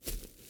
footsteps.ogg